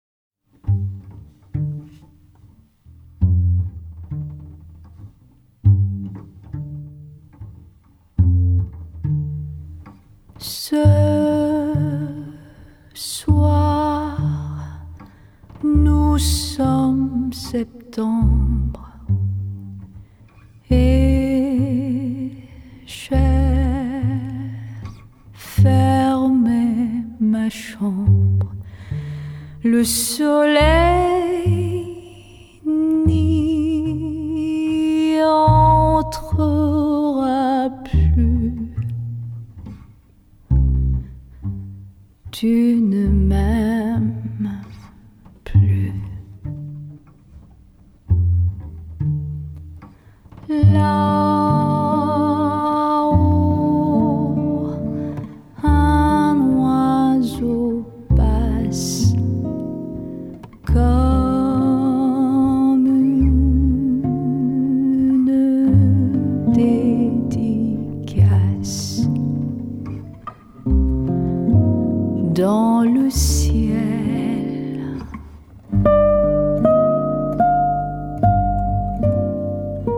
絕美的發燒女聲示範盤